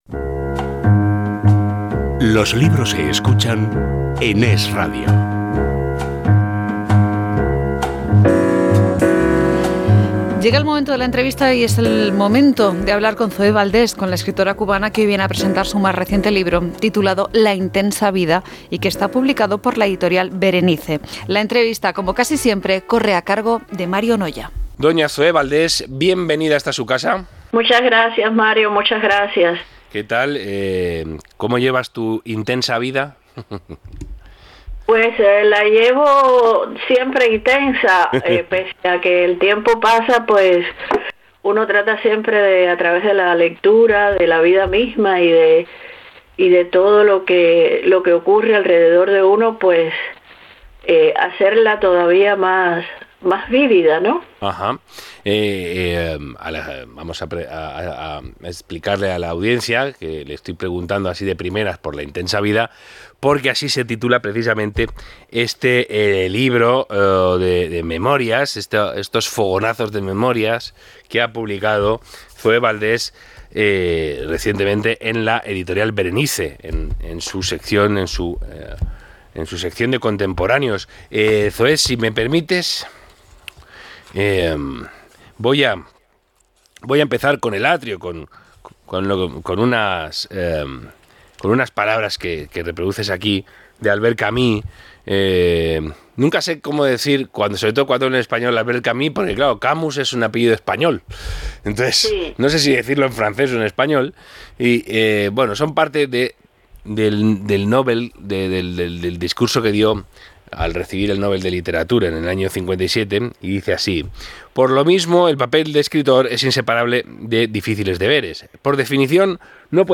Entrevista a Zoe Valdés por 'La intensa vida' - ZoePost